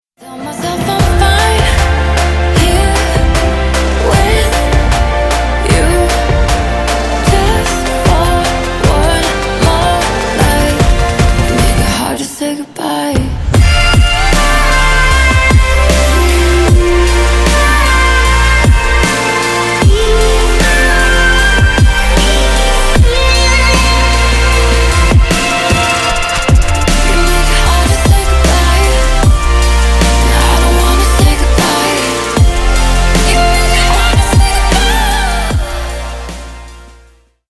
Громкие Рингтоны С Басами
Рингтоны Электроника